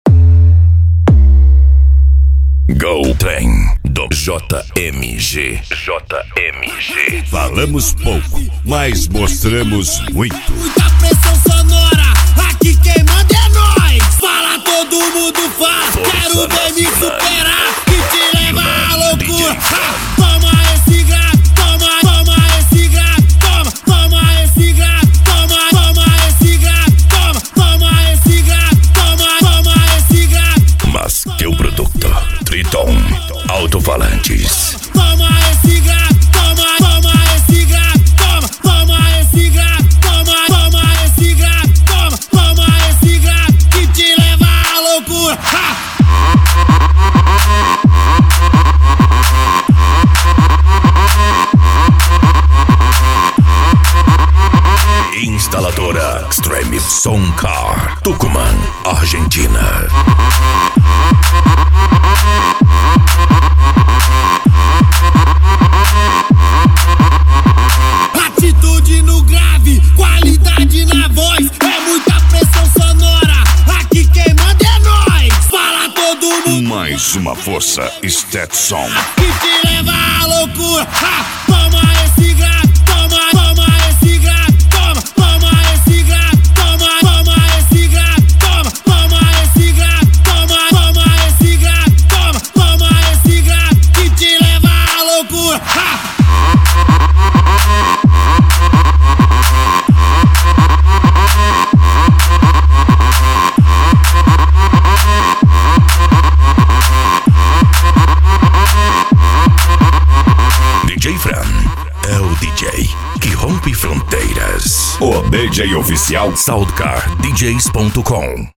Bass
Cumbia
PANCADÃO
Remix